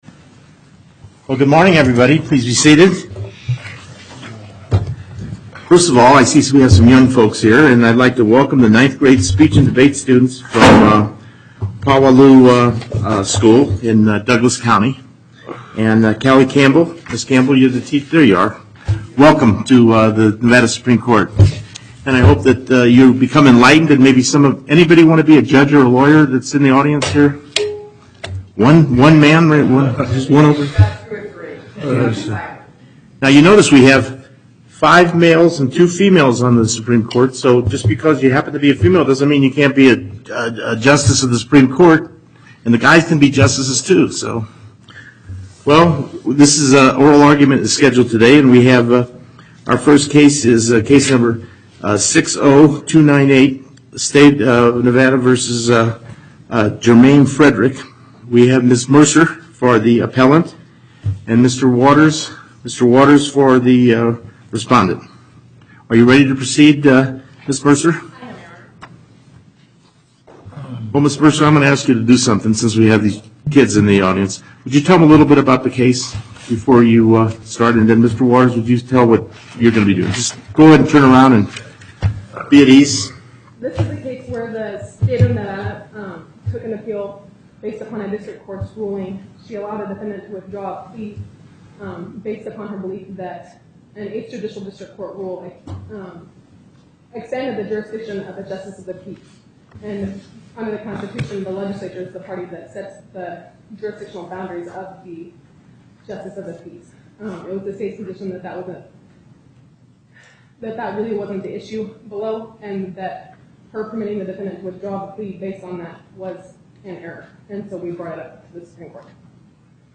Location: Carson City Before the En Banc Court, Chief Justice Cherry Presiding
as counsel for the Appellant